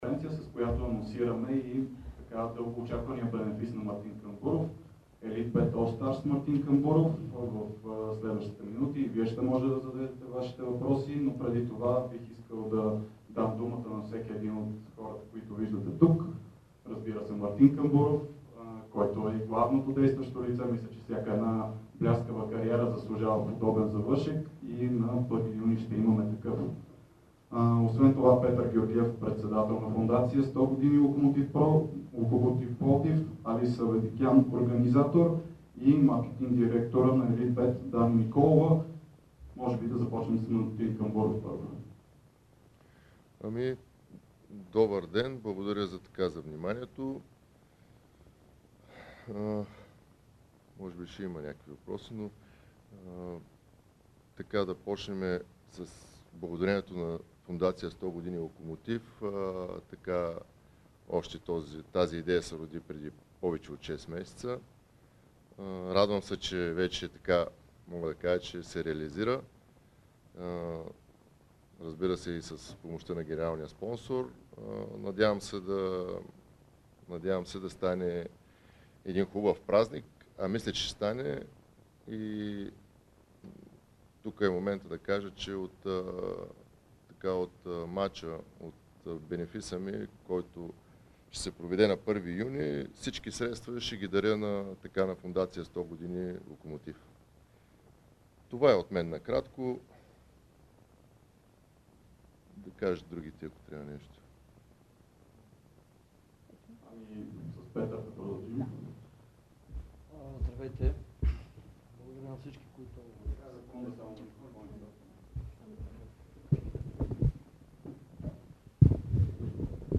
Голмайсторът №1 на българското първенство – Мартин Камбуров, коментира процесите в българския футбол по време на пресконференцията, на която анонсира своя бенефис, който ще се състои на стадион „Локомотив“ в Пловдив на 1 юни.